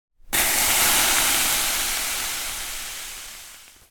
Звуки спа, атмосфера
Сауна, вода льется на камни и угли, шипение, мгновенный всплеск